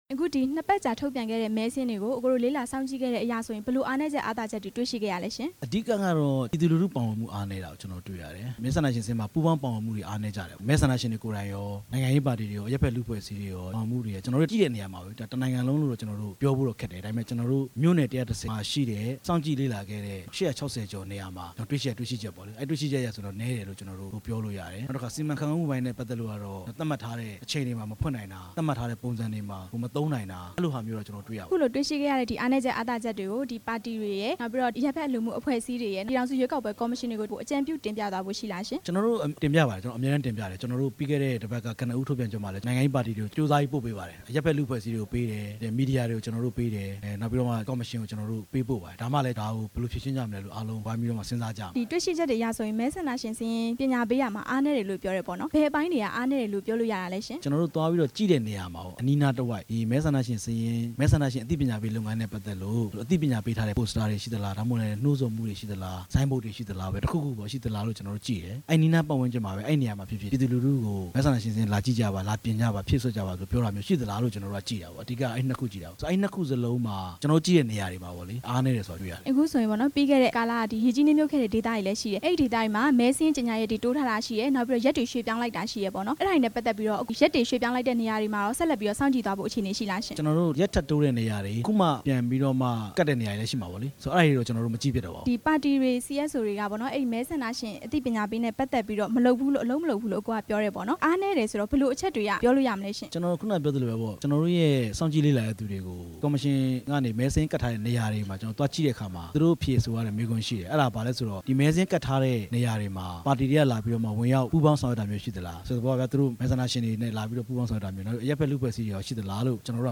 ရွေးကောက်ပွဲစောင့်ကြည့်နေတဲ့ PACE အဖွဲ့ရဲ့ သတင်းစာရှင်းလင်းပွဲ
တွေ့ဆုံမေးမြန်းထားပါတယ်။